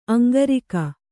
♪ aŋgarika